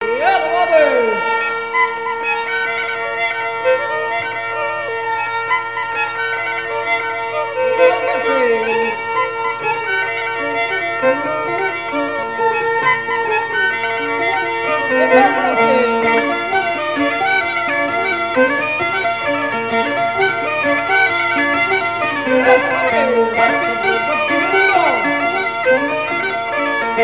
Mémoires et Patrimoines vivants - RaddO est une base de données d'archives iconographiques et sonores.
danse : branle : avant-deux
Airs joués à la veuze et au violon et deux grands'danses à Payré, en Bois-de-Céné
Pièce musicale inédite